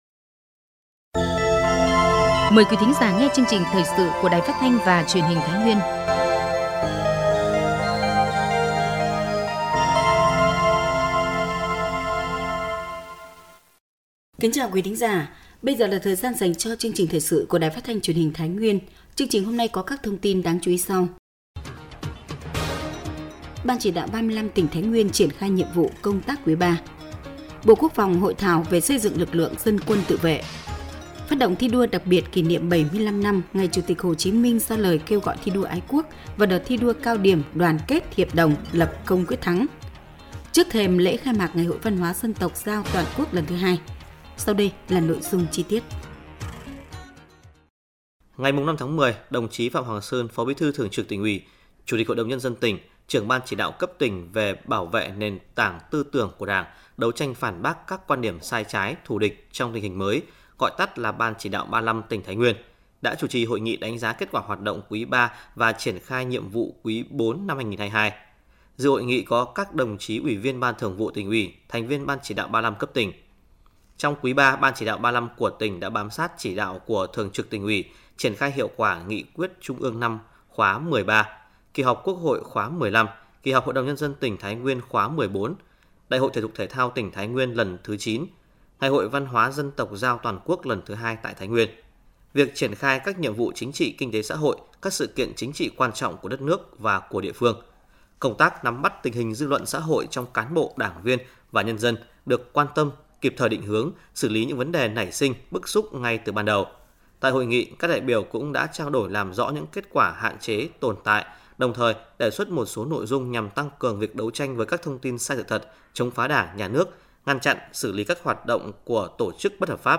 Thời sự tổng hợp Thái Nguyên ngày 06/10/2022